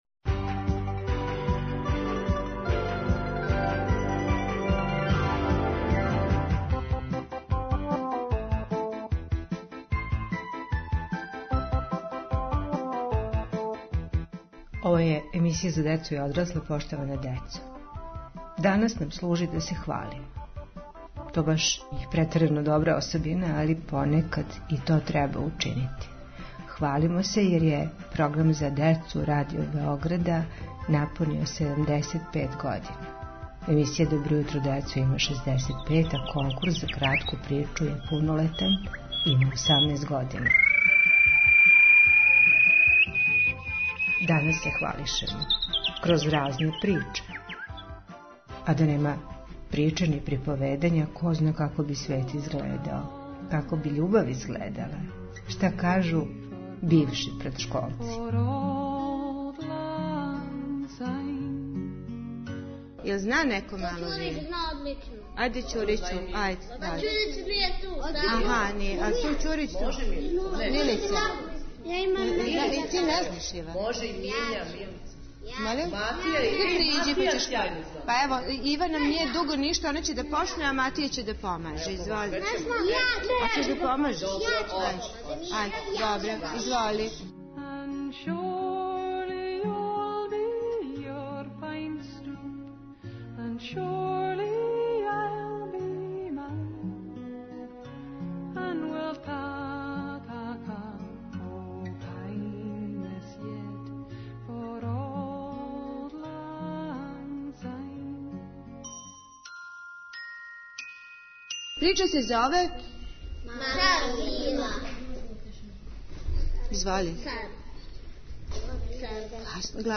Деца и победница конкурса нас воде, причом, кроз емисију.